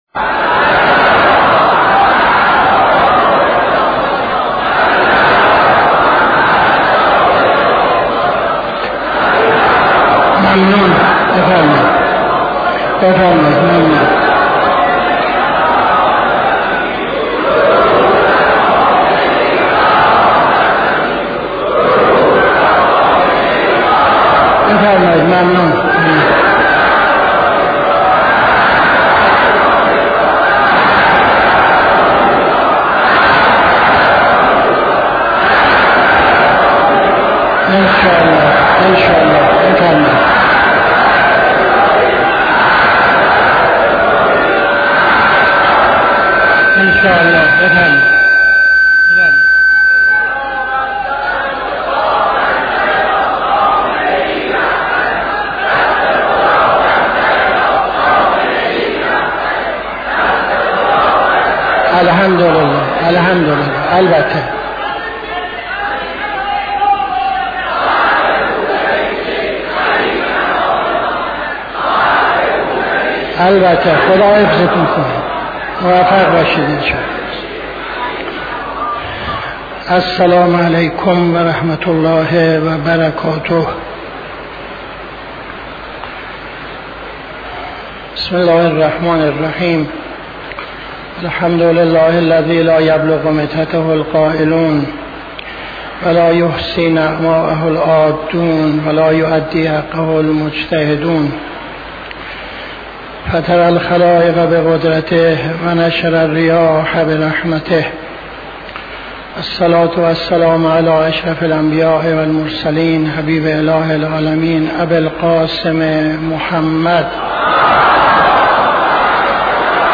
خطبه اول نماز جمعه 17-04-78